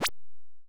Game-shot-light-gun.wav